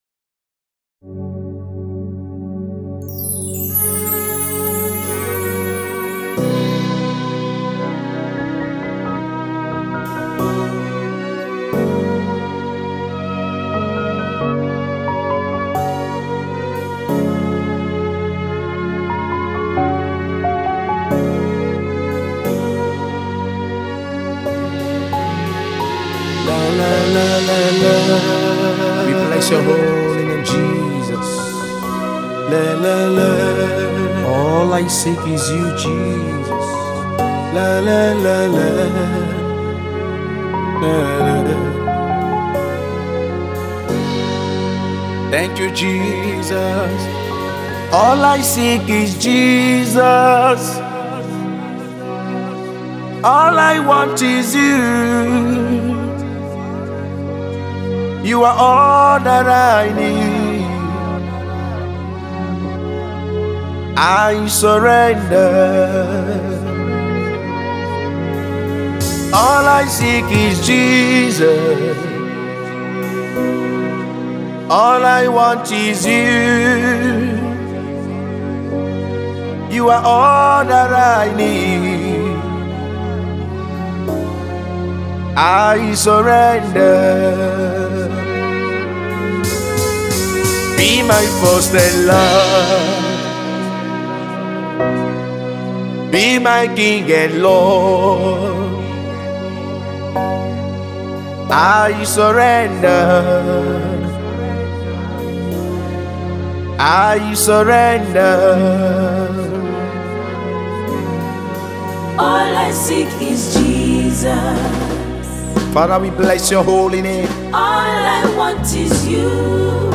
spirit filled worship song
a soul lifting song